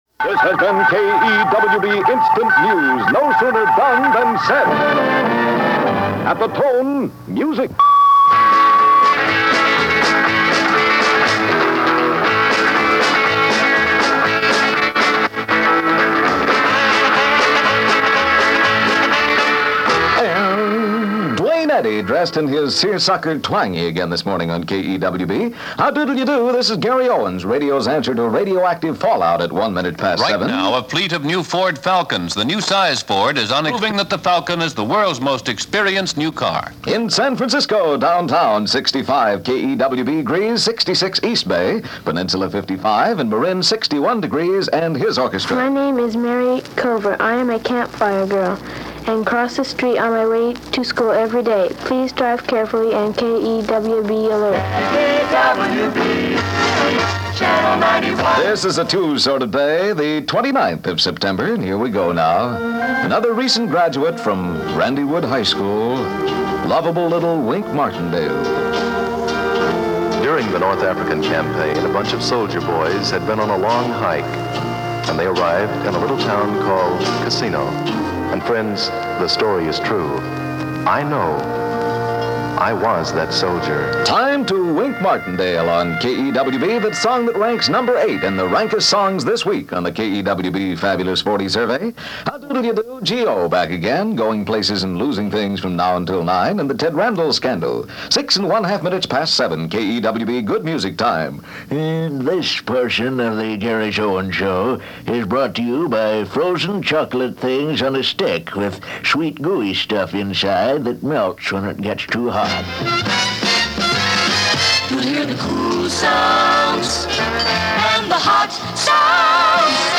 By way of tribute, here is a sample of what he was like during his stint in the Bay Area in September 1959 at sister-station KEWB in Oakland. This is what is known as a “scoped aircheck” in that the music is cut down, allowing the disc jockey to be the center of attention, for audition purposes.
He was the master of comic-deadpan and his delivery was impeccable.
Gary-Owens-KEWB-1959.mp3